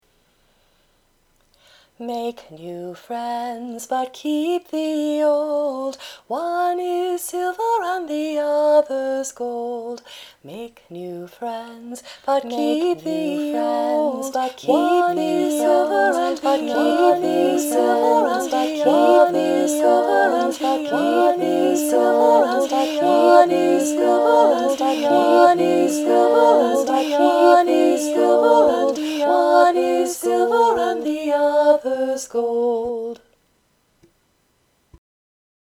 I’m reminded of the old Girl Scout round: